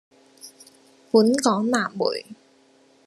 Голоса - Гонконгский 297